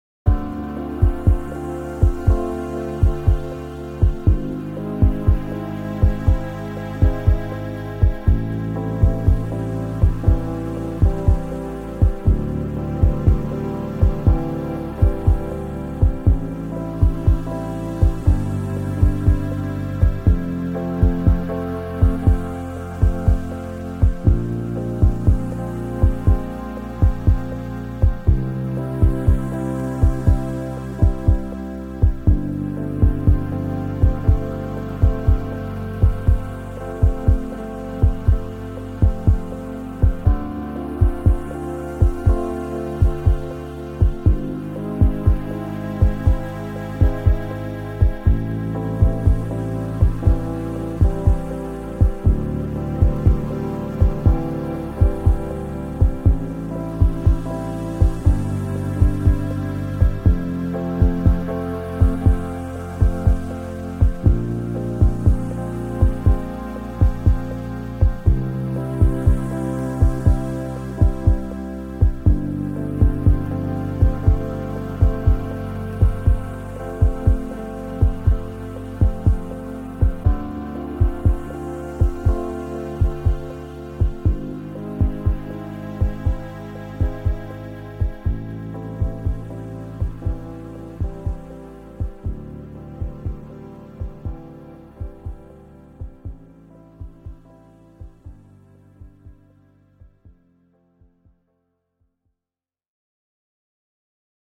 1. The heart-beat rhythm of the kick drum.
But they manage to make it sound serene in this song.
2. The yummy synth layers.
3. The odd chord progression.
Tags2000s 2001 dance France house